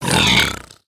Minecraft Piglin Deaths Sound Button - Free Download & Play